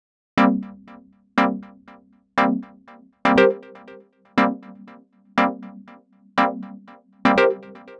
Ew Brass Syn.wav